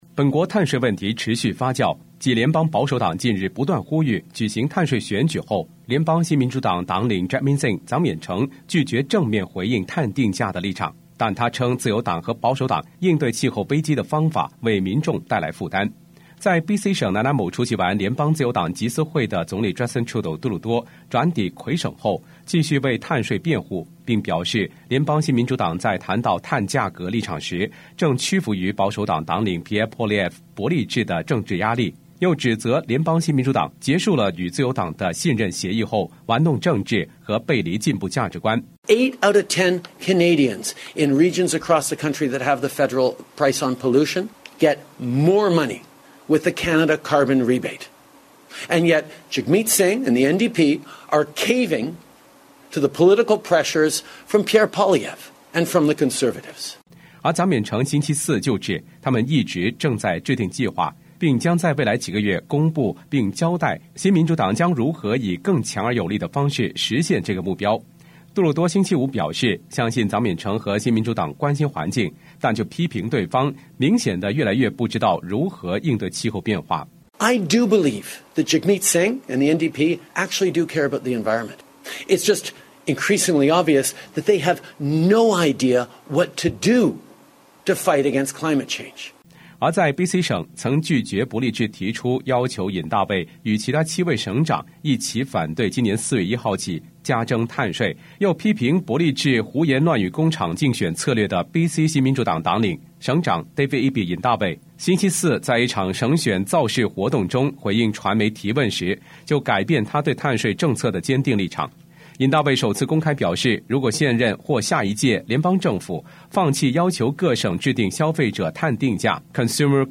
Canada/World News 全國/世界新聞
報道